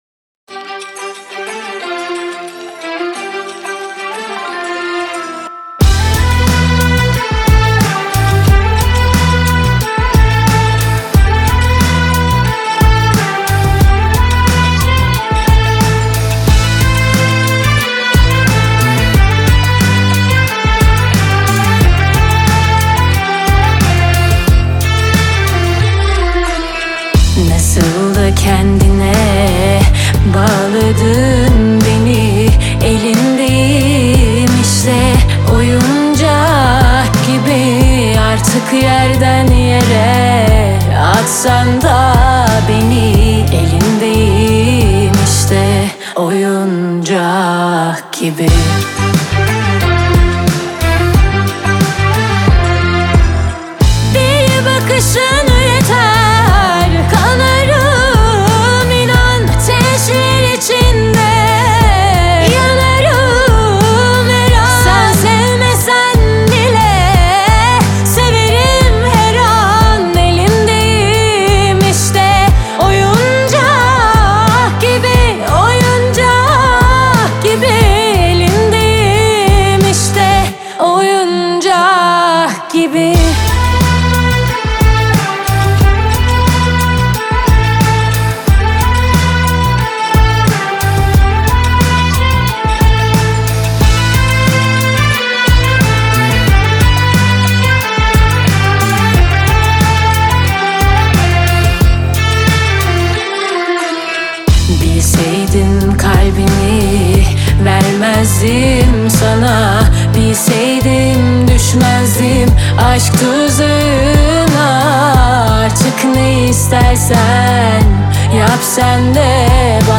Трек размещён в разделе Турецкая музыка / Поп.